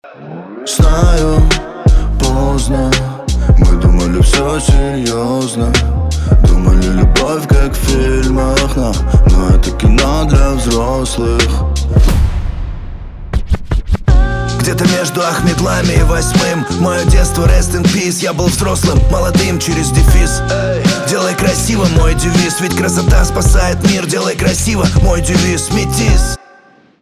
русский рэп
битовые , басы
пианино